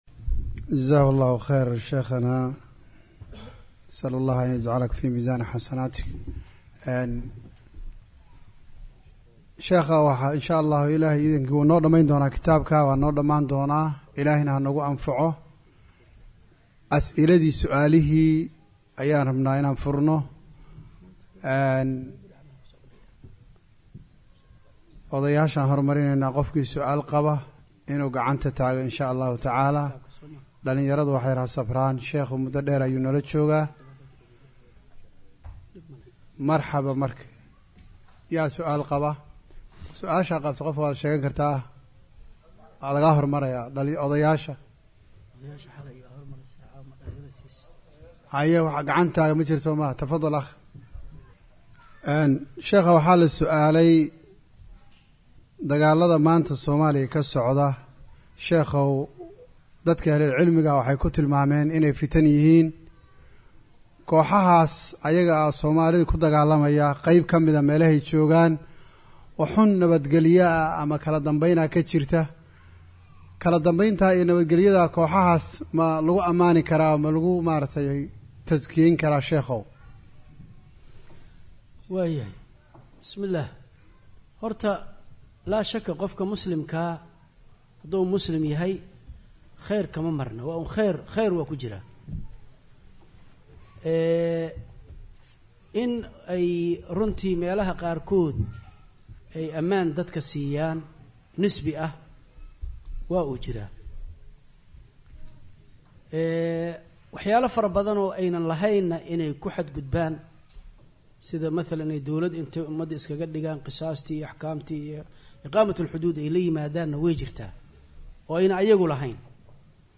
suaalo-jawaabo-4.mp3